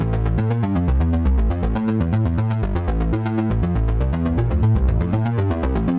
Index of /musicradar/dystopian-drone-samples/Droney Arps/120bpm
DD_DroneyArp1_120-A.wav